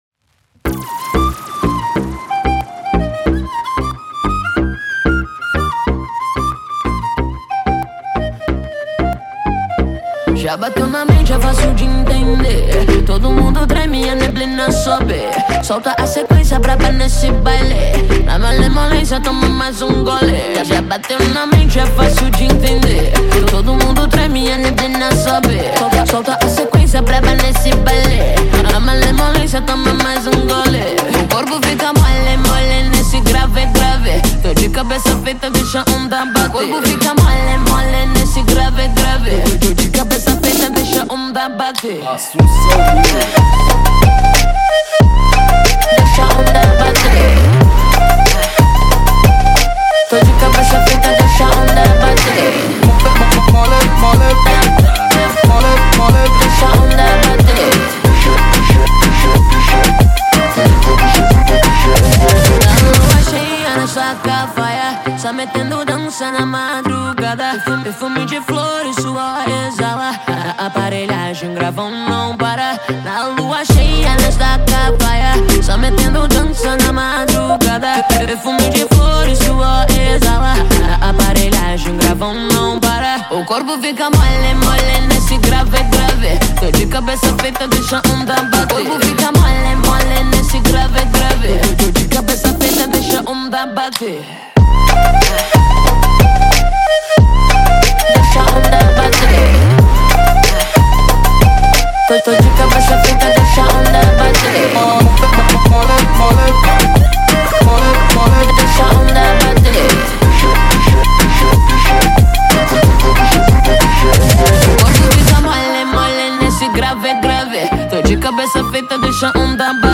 Gênero: Pop